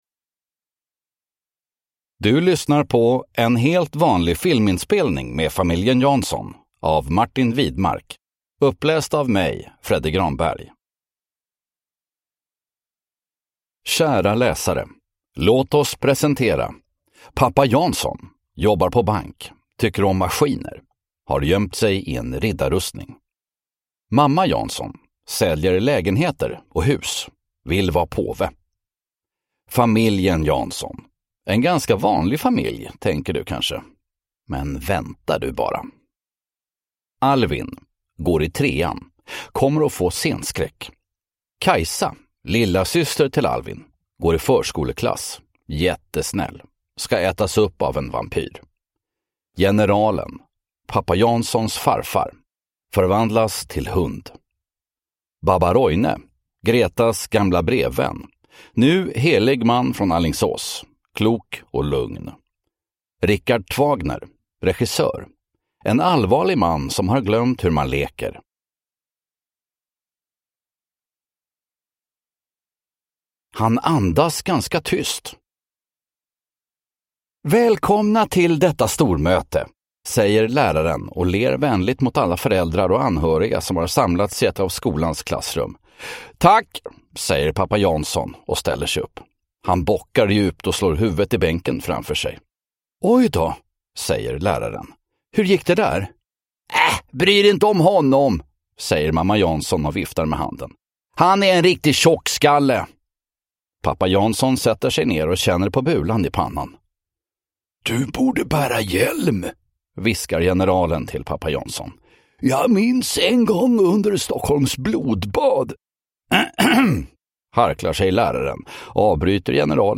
En helt vanlig filminspelning med familjen Jansson (ljudbok) av Martin Widmark